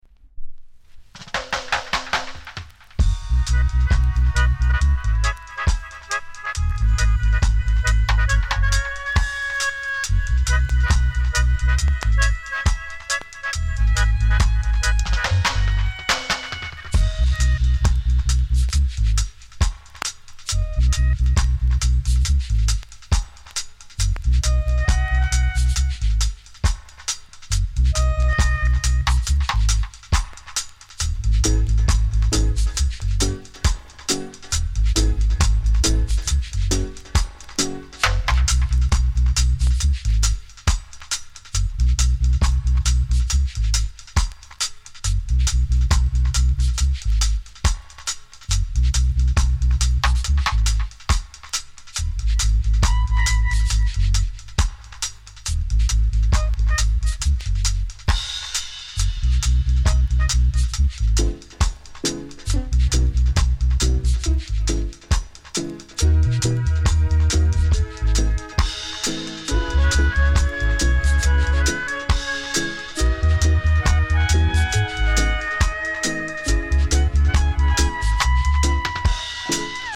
概ね美盤ですが、両面 音に影響ない 軽いセンターずれ。